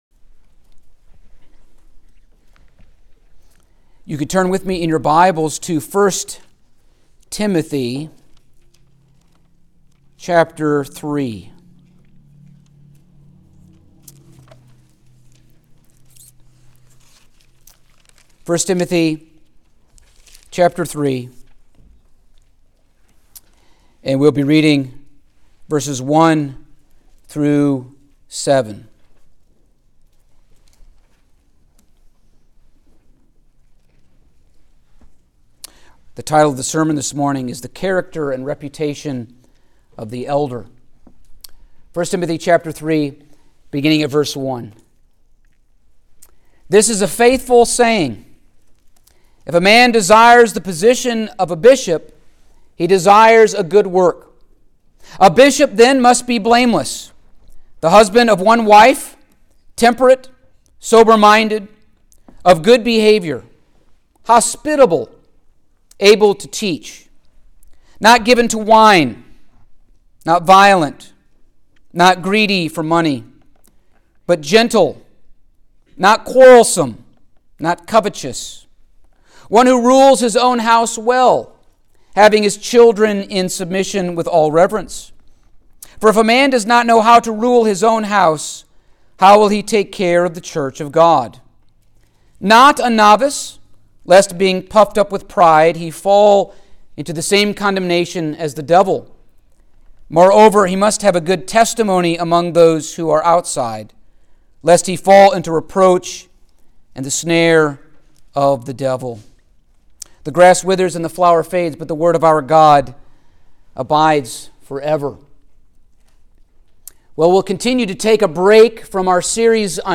Passage: 1 Timothy 3:1-7 Service Type: Sunday Morning